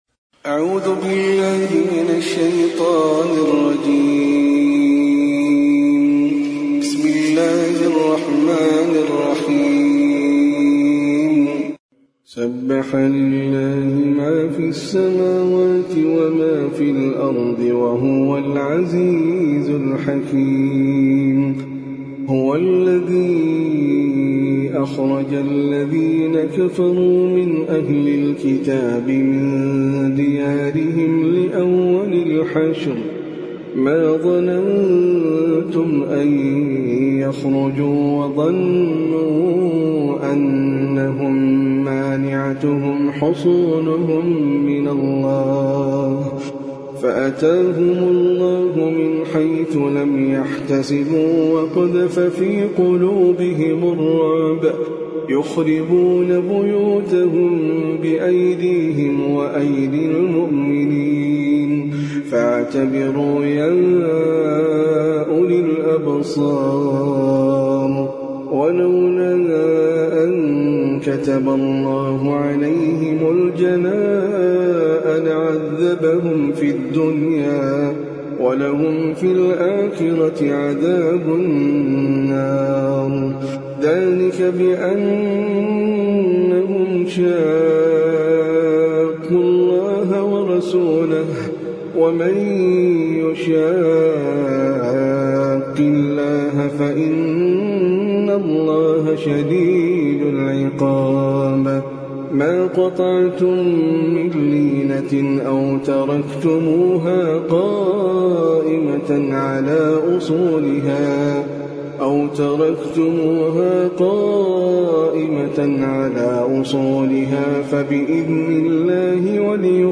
سورة الحشر - المصحف المرتل (برواية حفص عن عاصم)
جودة عالية